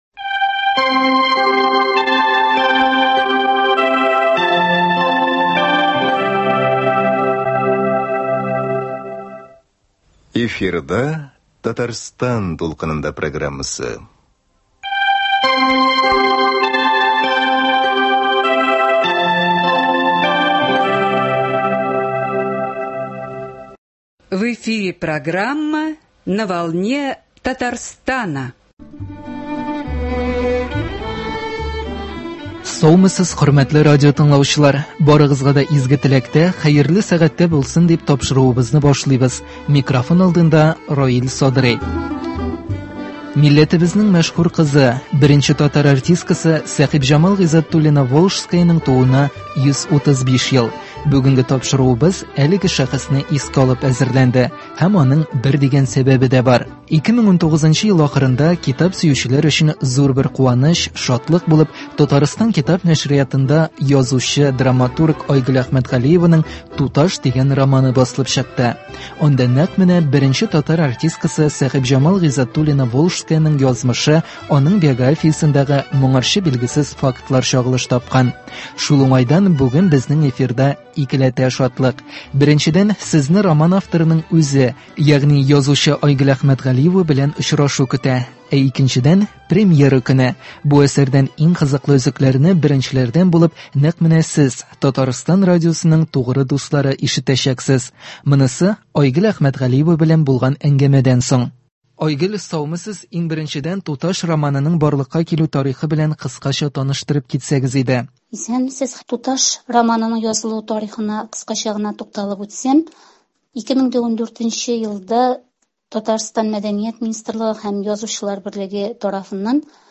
Бу әсәрдән иң кызыклы өзекләрне беренчеләрдән булып нәкъ менә сез, Татарстан радиосының тугры дуслары ишетәчәксез.